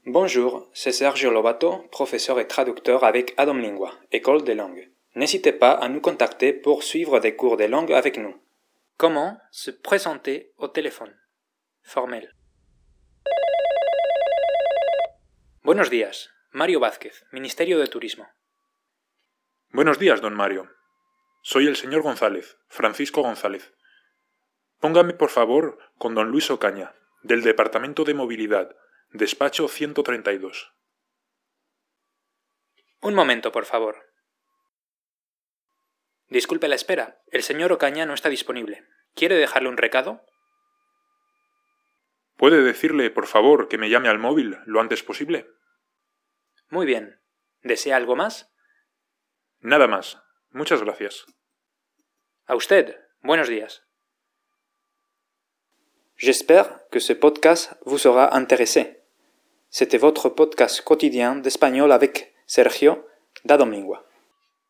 En voici la transcription (échange formel):